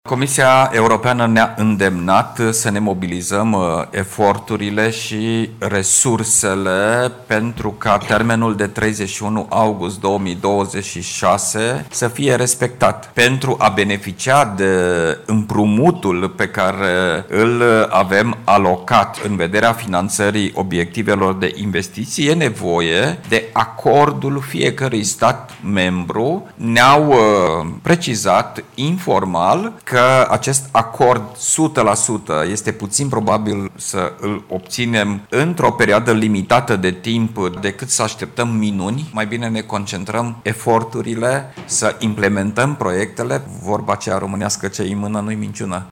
Ministrul Investițiilor și Proiectelor Europene, Marcel Boloș: „Ar trebui să ne mobilizăm eforturile”